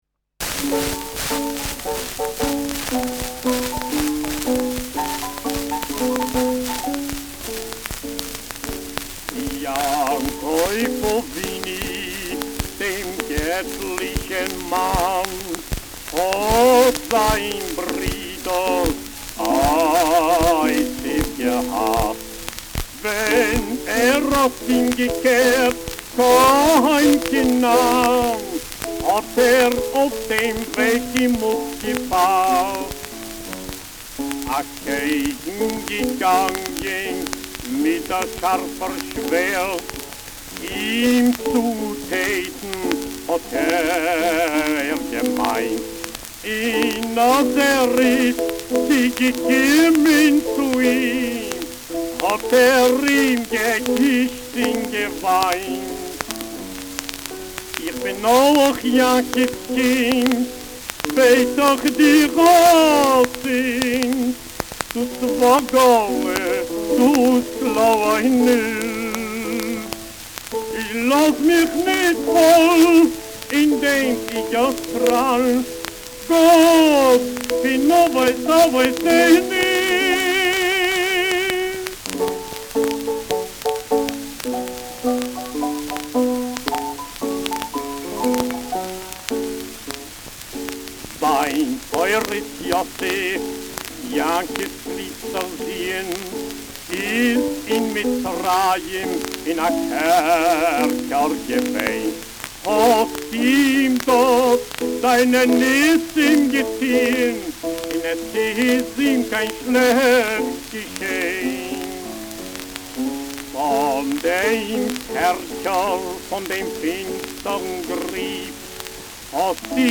Schellackplatte
präsentes Rauschen : präsentes Knistern : Nadelgeräusch : gelegentliches Knacken
[Lwiw] (Aufnahmeort)